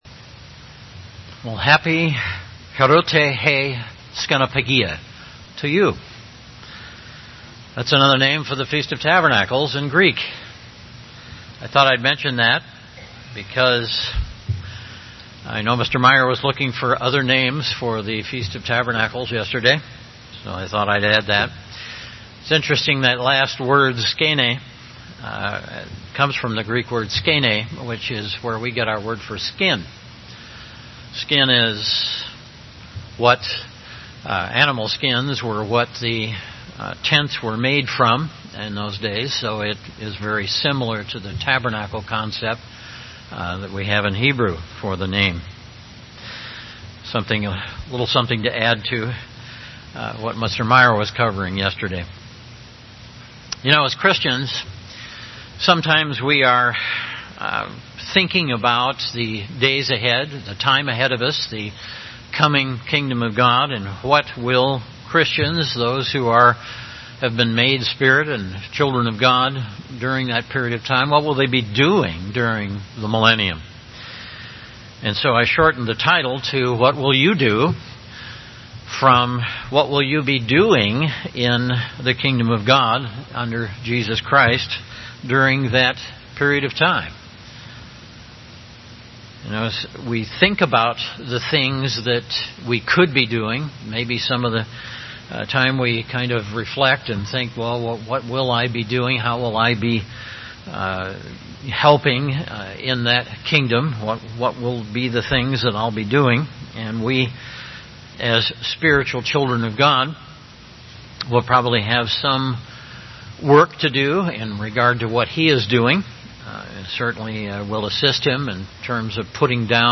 This sermon was given at the Wisconsin Dells, Wisconsin 2017 Feast site.